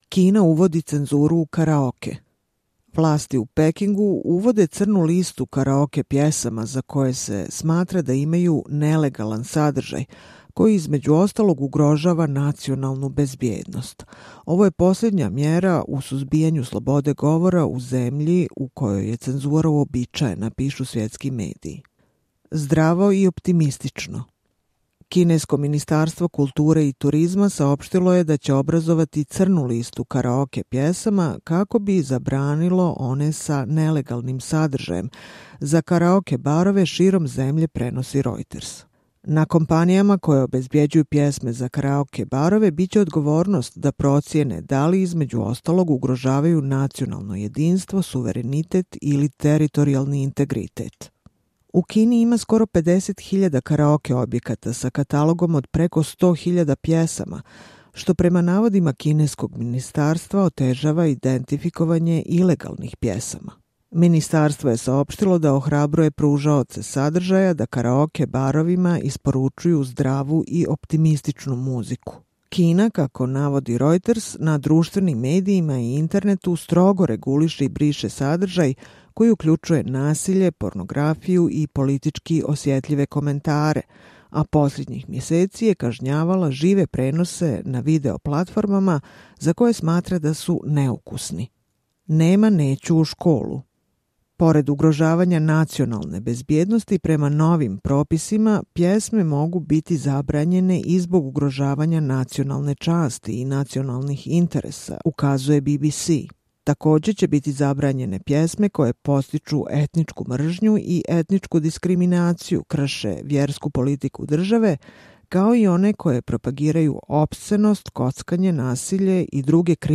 Čitamo vam: Kina uvodi cenzuru u karaoke